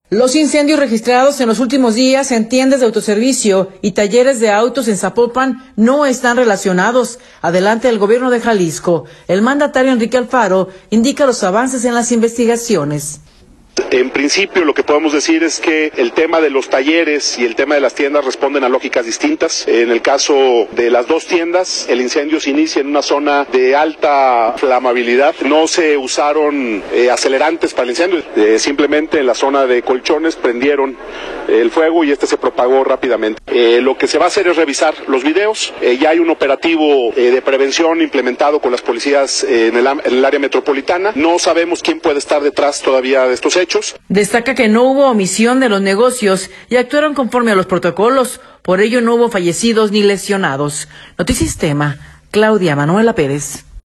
Los incendios registrados en los últimos días en tiendas de autoservicio y talleres de autos en Zapopan, no están relacionados, adelanta el Gobierno de Jalisco. El mandatario, Enrique Alfaro, indica los avances en las investigaciones.